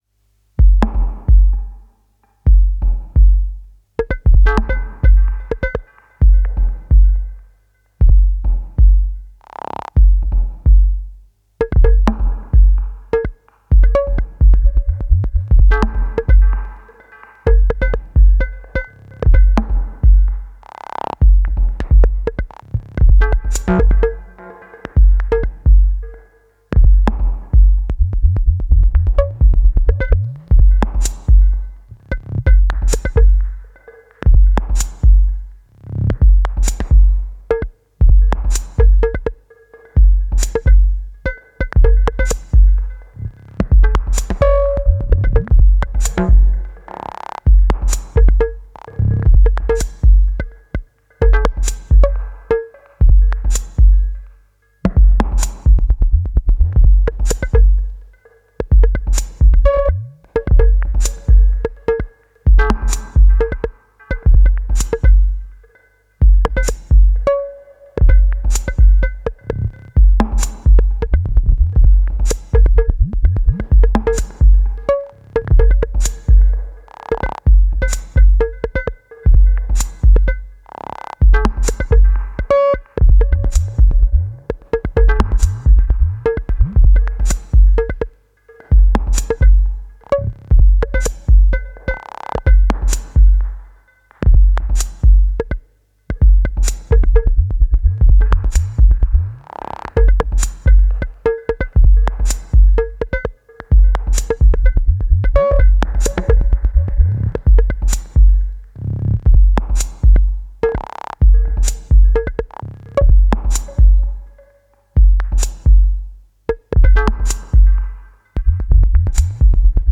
No samples aside from a 0,1 Hz sine wave SCW used for modulating stuff.
The rimshot and cowbell engines can offer so much sonically. Lots of cool textures are to be found there, especially with low tunings and fast modulation.
That low flapping duggi-type sound was the impulse pinging a filter with some attack and decay on the filter env.
The ratcheting sound was also the impulse engine retriggered with a ramp up lfo in trig mode modulating the impulse decay, to mimic a filter sweep, plus some actual filtering.
The hats were first the open the then the closed which cuts the open off, slightly panned to the opposing sides.